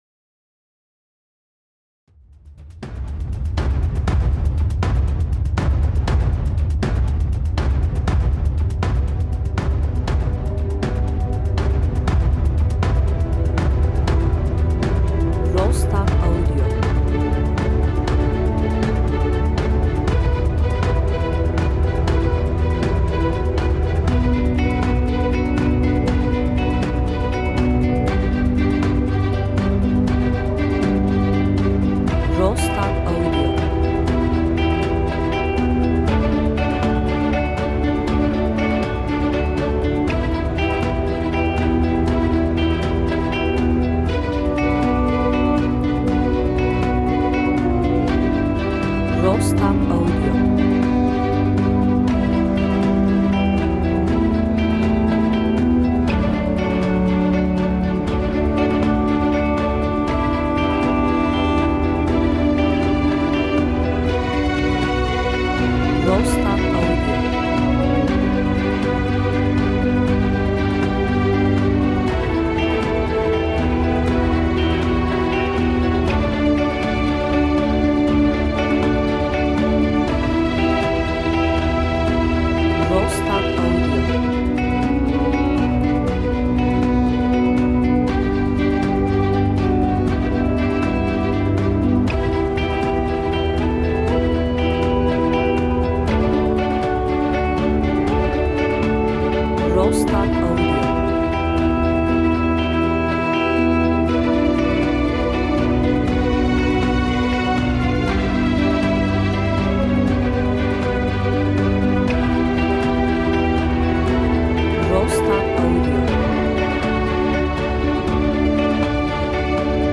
enstrümantal hareketli müzik